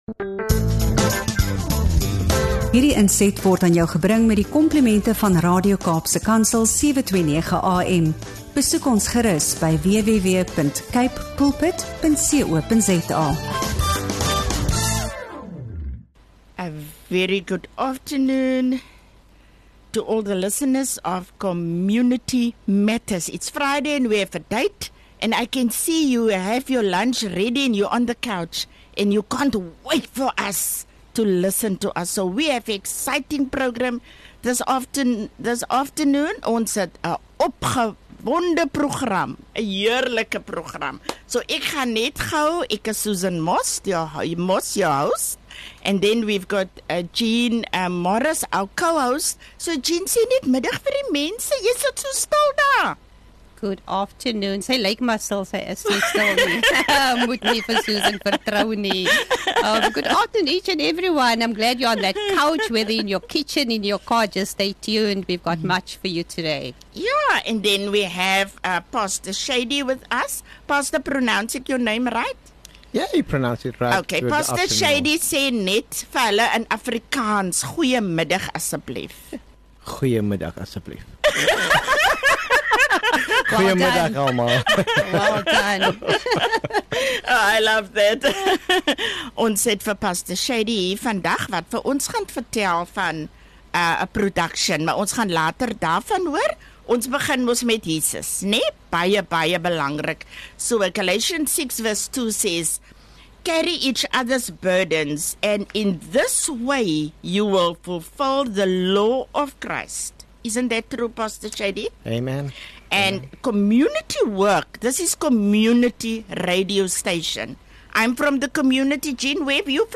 If you’re passionate about social justice, community upliftment, and real stories of transformation, you won’t want to miss this inspiring conversation.